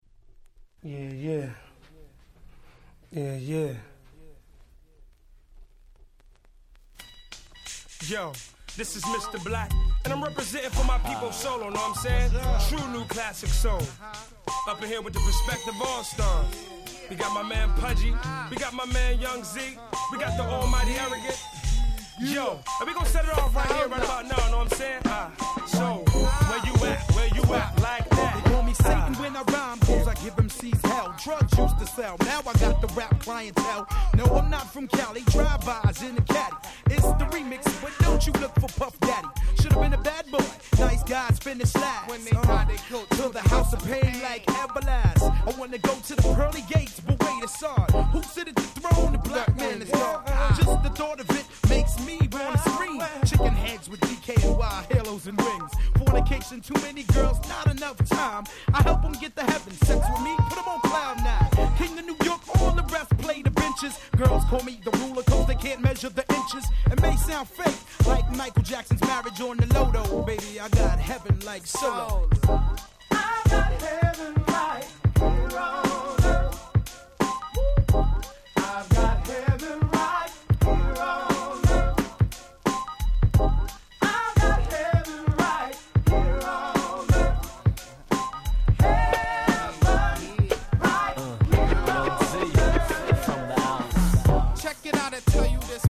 95' Nice Hip Hop Soul !!